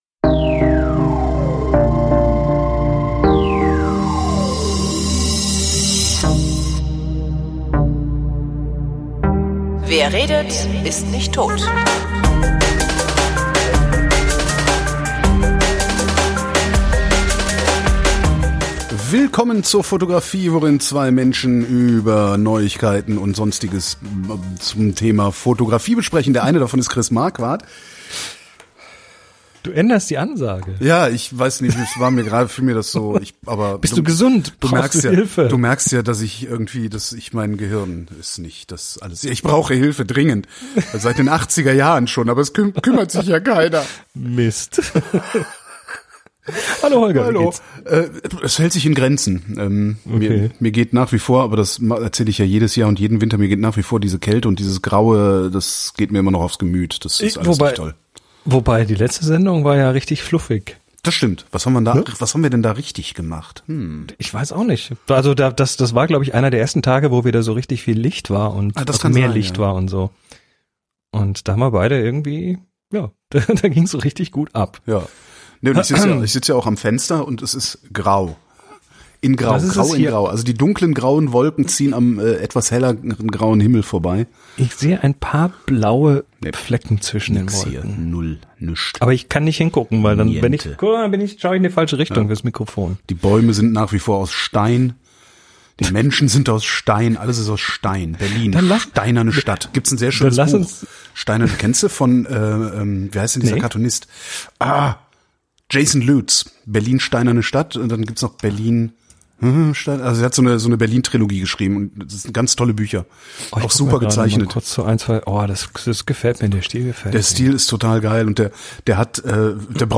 Darum klingen wir vermutlich insgesamt zuversichtlicher, als man erwarten würde.